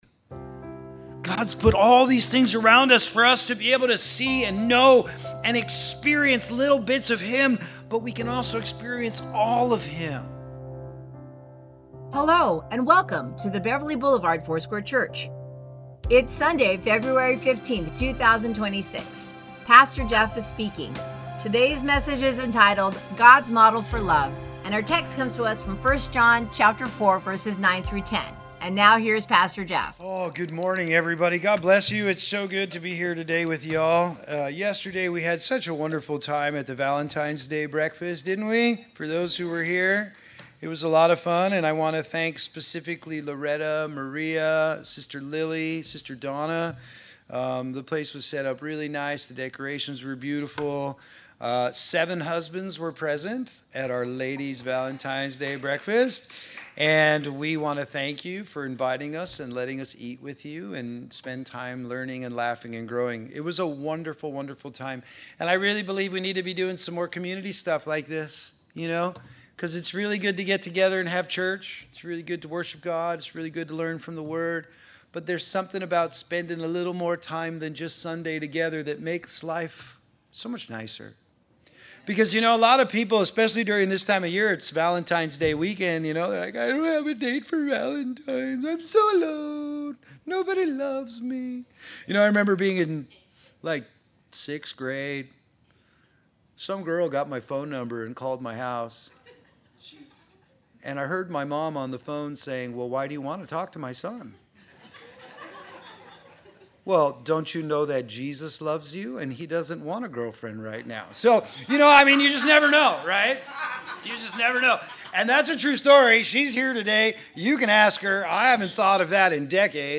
Sermons | Beverly Boulevard Foursquare Church